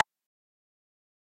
keyboard2.mp3